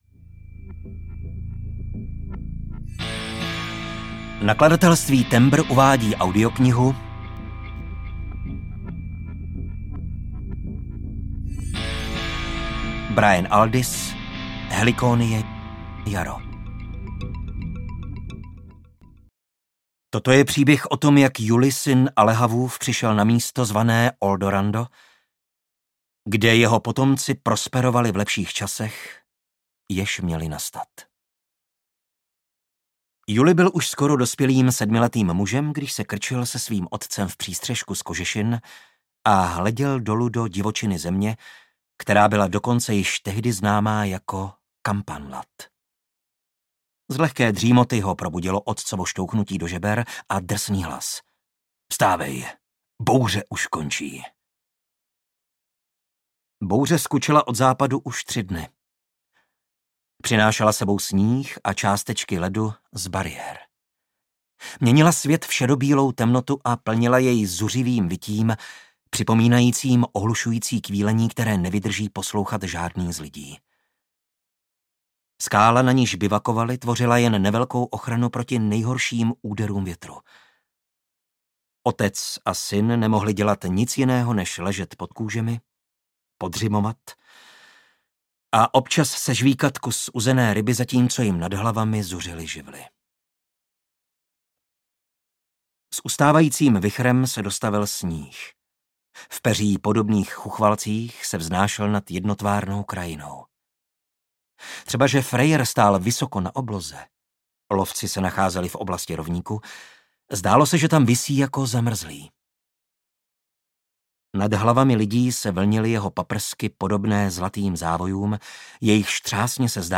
Helikonie - Jaro audiokniha
Ukázka z knihy
helikonie-jaro-audiokniha